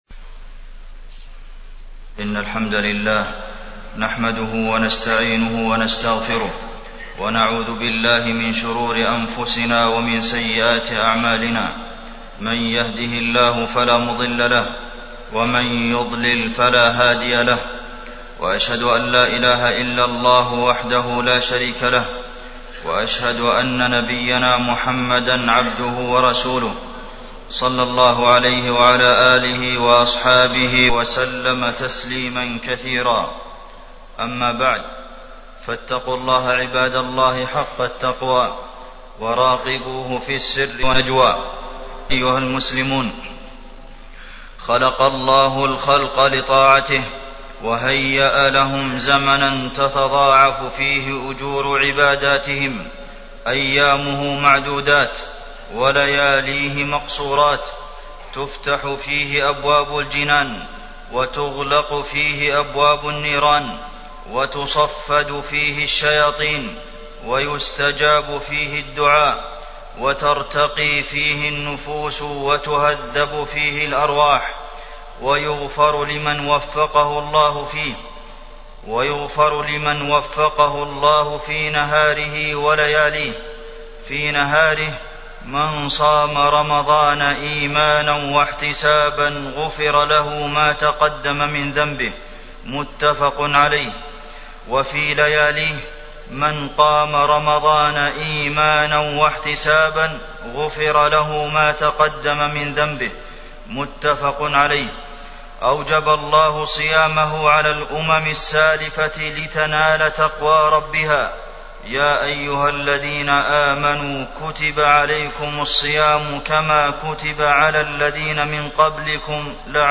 تاريخ النشر ٩ رمضان ١٤٢٨ هـ المكان: المسجد النبوي الشيخ: فضيلة الشيخ د. عبدالمحسن بن محمد القاسم فضيلة الشيخ د. عبدالمحسن بن محمد القاسم القرآن ومنزلته